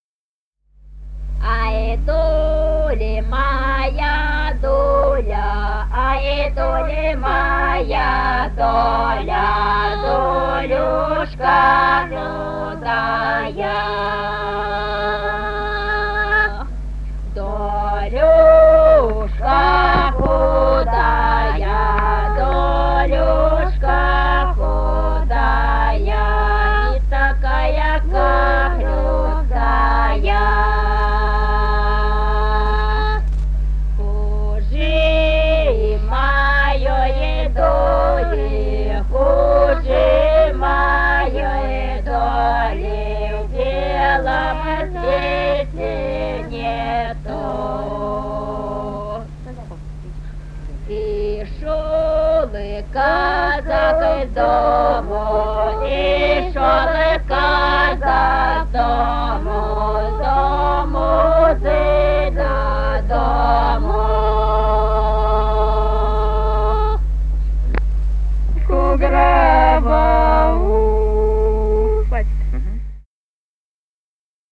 Русские народные песни Красноярского края.
«Ай, доля моя, доля» (свадебная). с. Тасеево Тасеевского района.